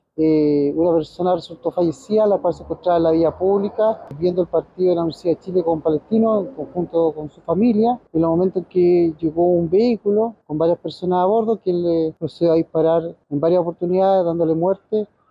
El fiscal ECOH, Rodrigo Moya, señaló que la víctima fue atacada mientras estaba junto a su familia viendo el partido entre Palestino y Universidad de Chile.